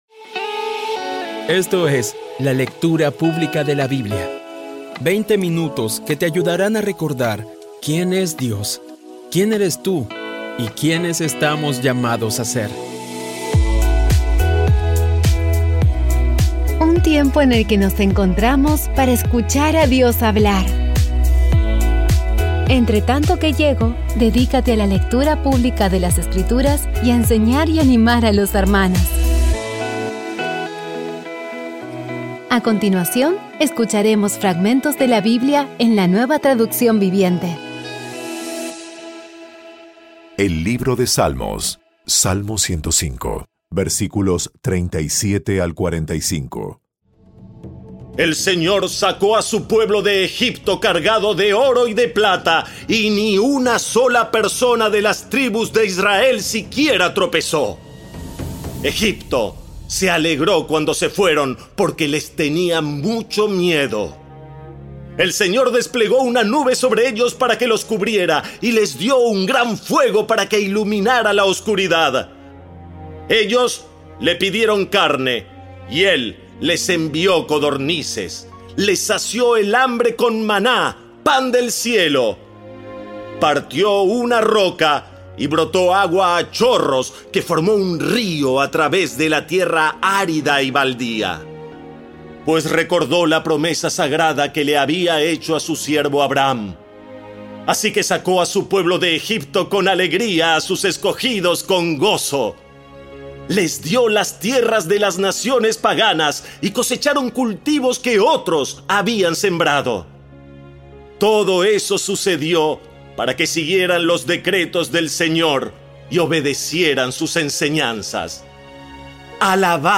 Audio Biblia Dramatizada Episodio 261
Poco a poco y con las maravillosas voces actuadas de los protagonistas vas degustando las palabras de esa guía que Dios nos dio.